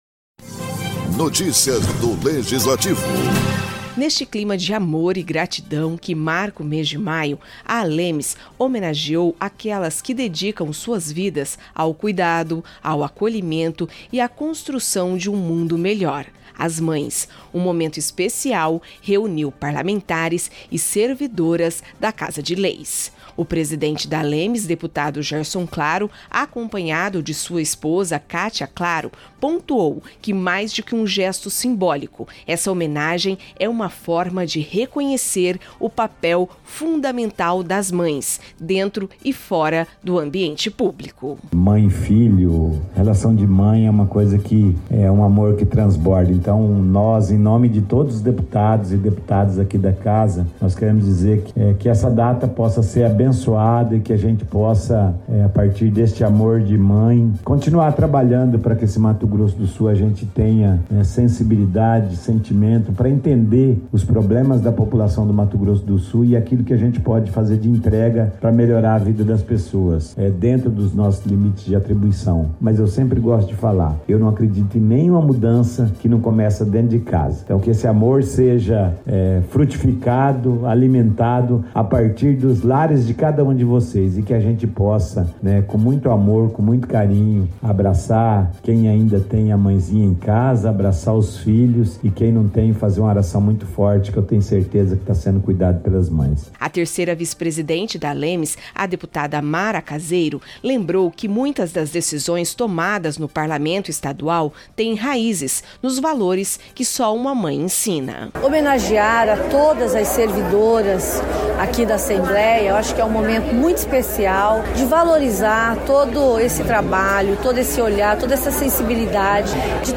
Parlamentares e servidoras participaram do encontro, que teve discursos comoventes, homenagens e uma apresentação emocionante do Coral da ALEMS, reforçando o papel fundamental das mães na vida pública e na sociedade.